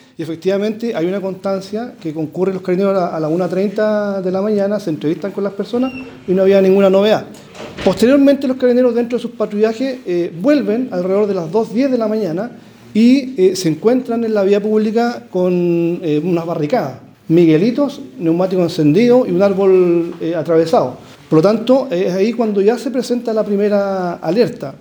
Así lo dijo el jefe de Zona Carabineros Bío Bío, general Renzo Miccono, quien confirmó que funcionarios policiales realizaron una ronda solo 30 minutos antes de que se desatara el hecho de violencia.